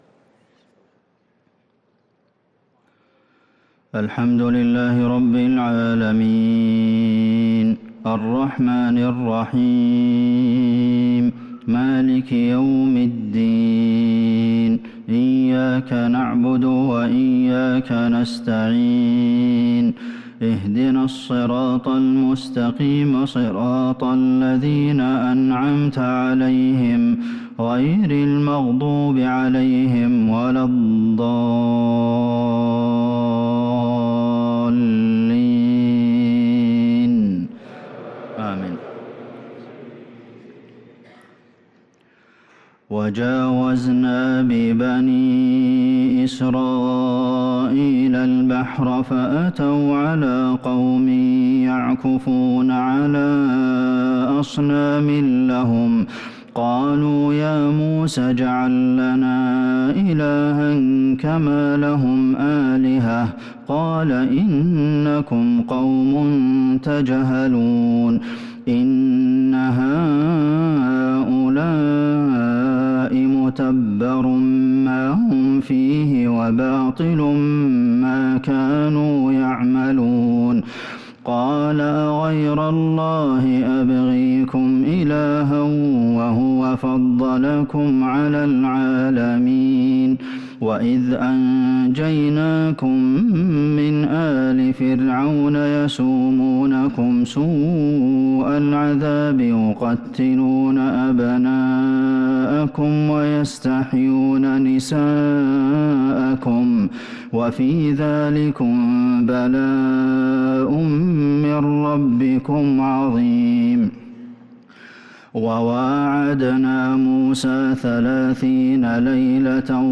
صلاة العشاء للقارئ عبدالمحسن القاسم 30 ذو الحجة 1443 هـ